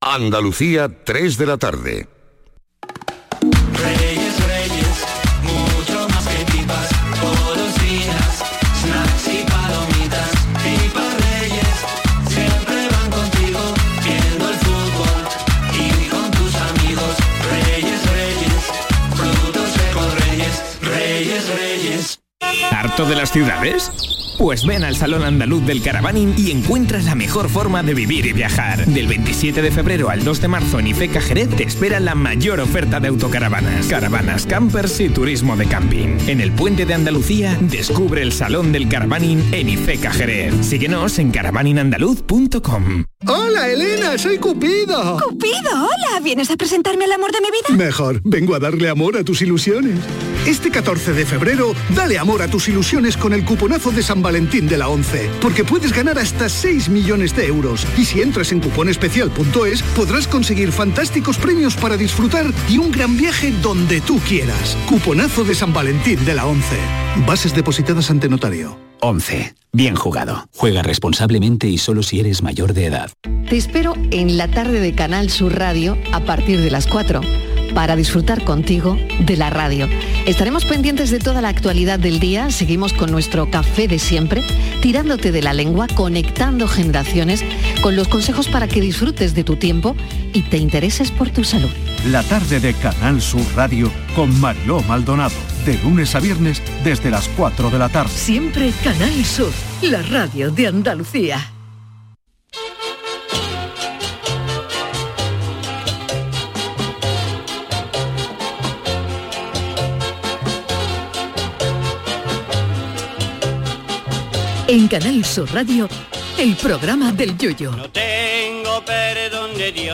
Escucha la versión más surrealista de la actualidad y la música que no te imaginas. Con el humor más delirante.